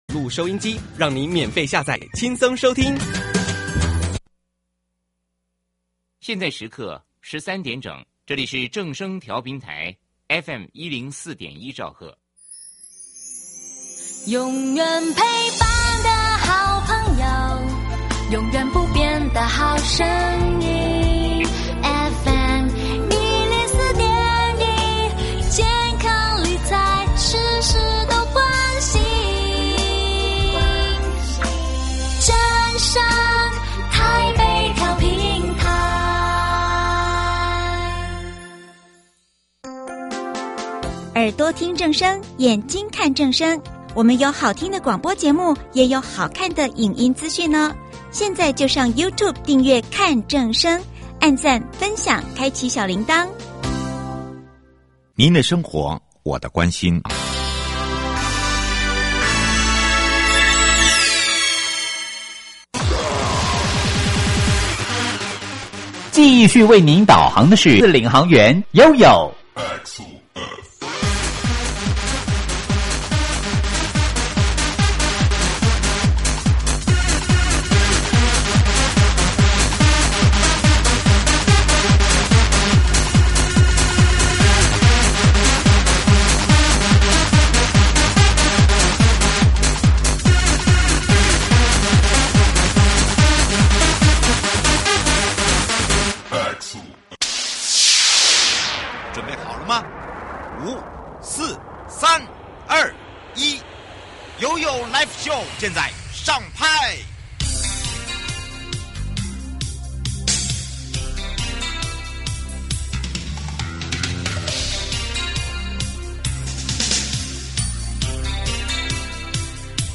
受訪者： 營建你我他 快樂平安行~七嘴八舌講清楚~樂活街道自在同行!(二) 「樂活街道自在同行」人本環境改善計